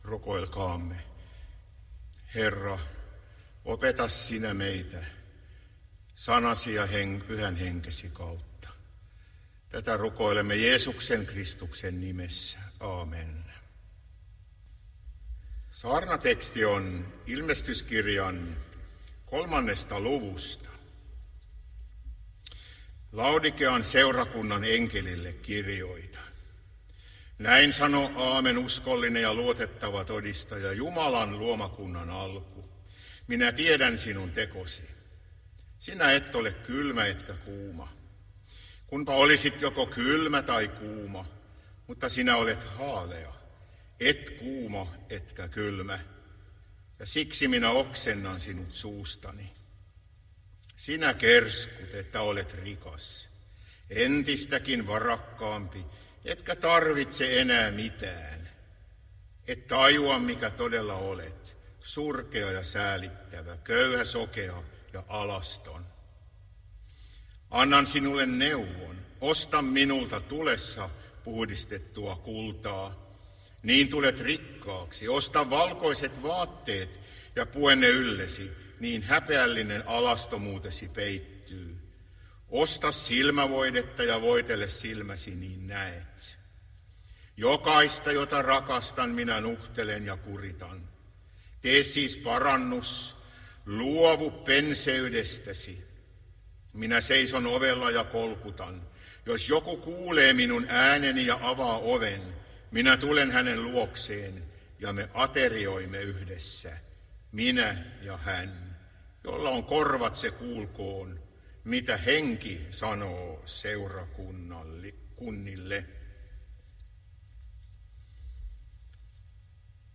Kristiinankaupunki